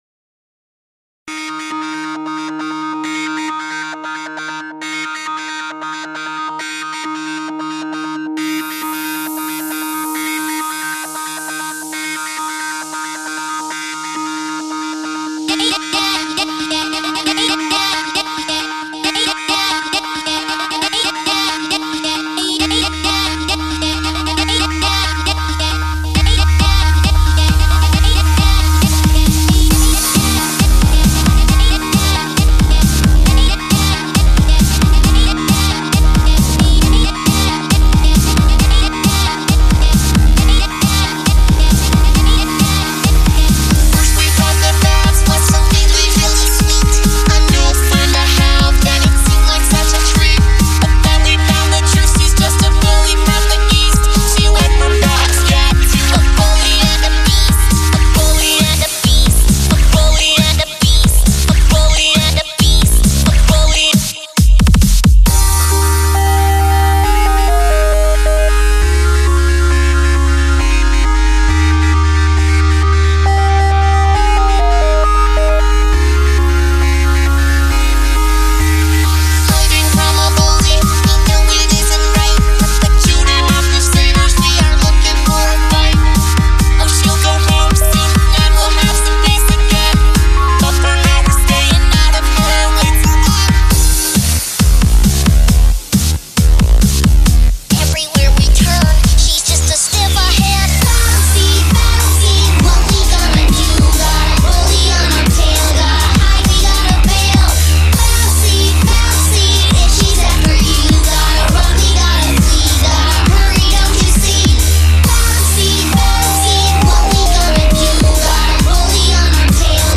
Jumping on the remix train for this.